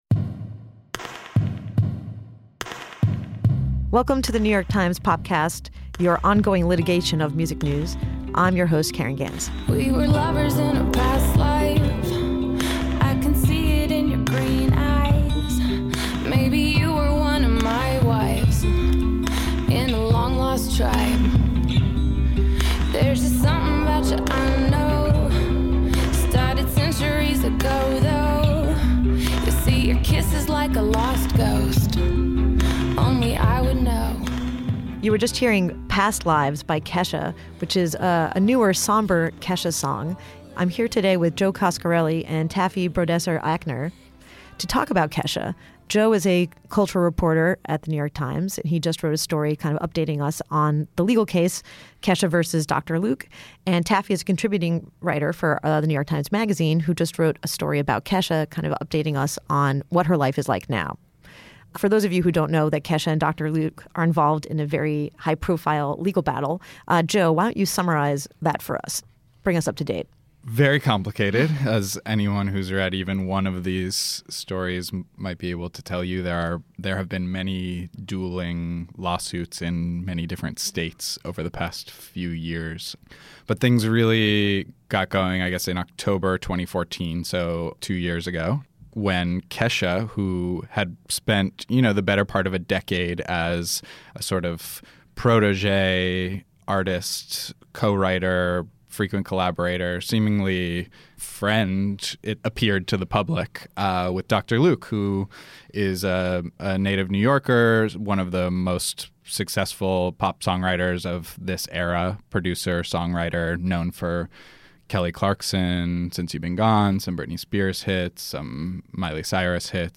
The pop star has been locked in an ugly contract dispute with Dr. Luke; two writers who have examined her case break down the pop star’s situation.